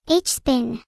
Add neuro voicepack